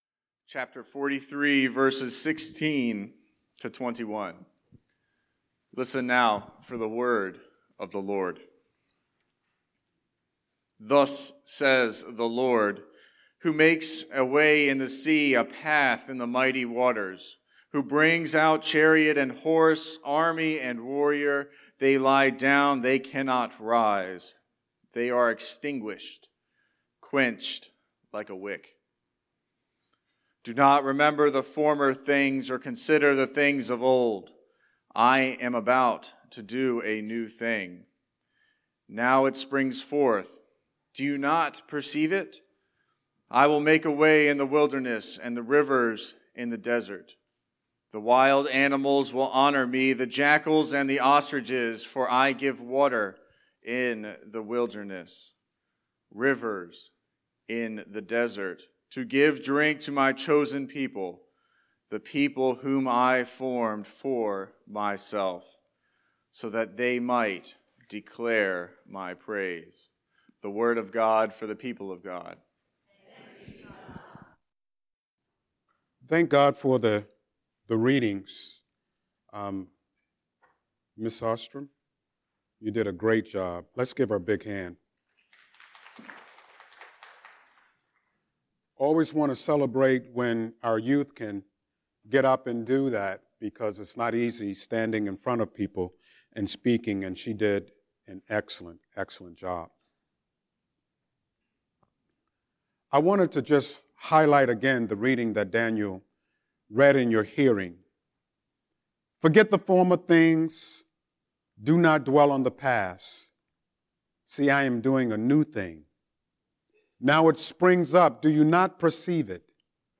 03-13-Scripture-and-Sermon.mp3